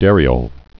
(dărē-ōl)